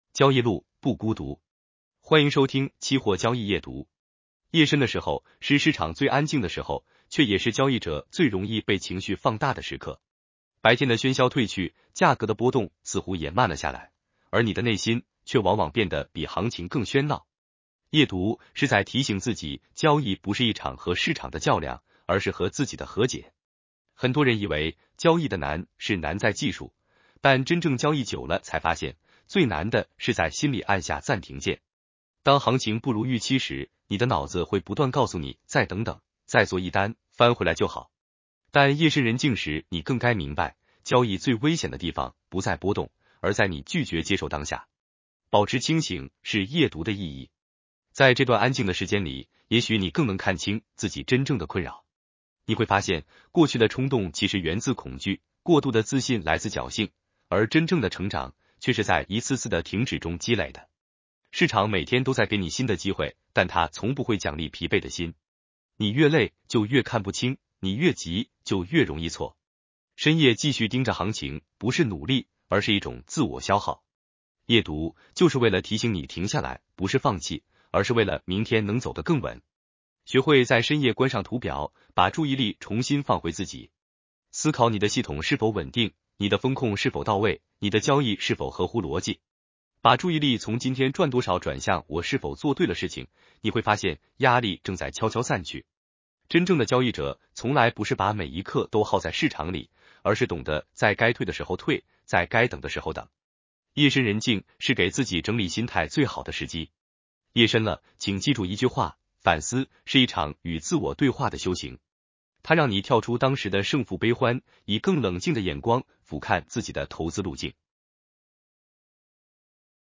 男生普通话版 下载mp3
（AI生成）